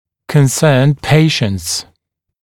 [kən’sɜːnd ‘peərənts][кэн’сё:н ‘пэарэнтс]обеспокоенные родители